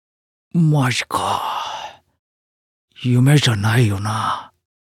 Sleep Talking
Chat Voice Files